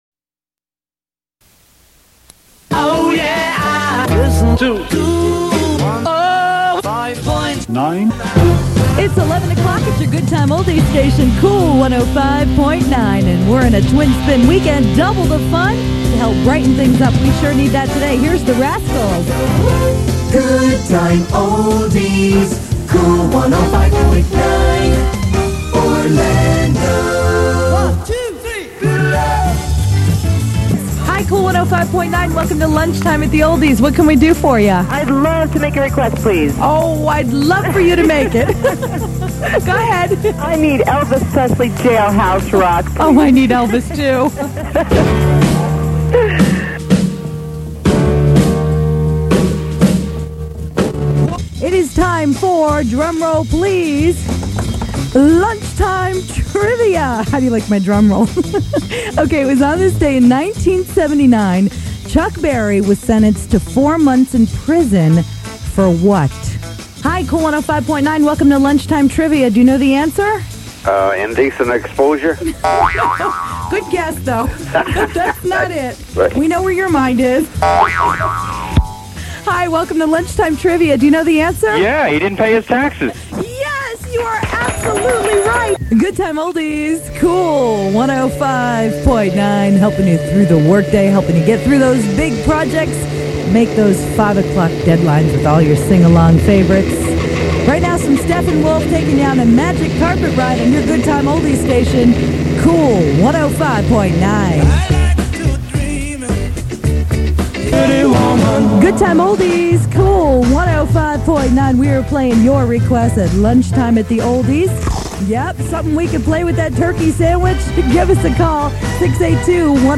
Voice Track Demos
(Aircheck)
(Good Time Oldies)